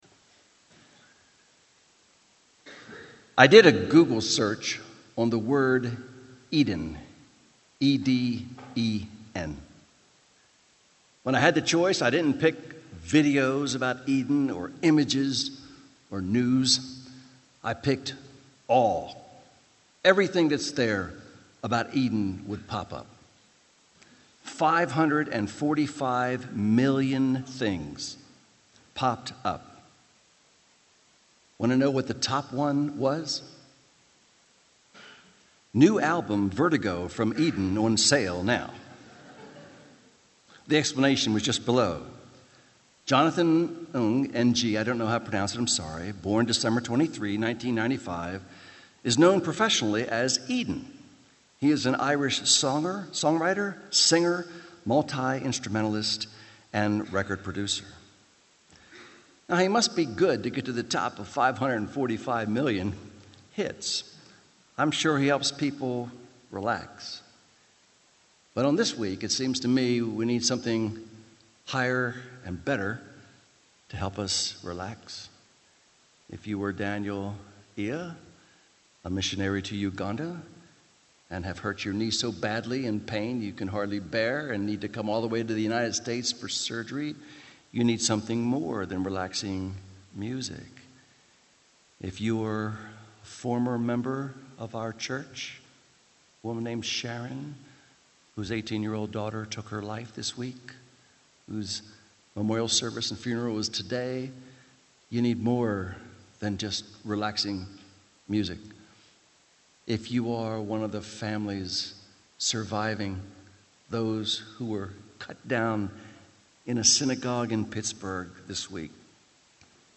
Sermons on Revelation 22:1-3 — Audio Sermons — Brick Lane Community Church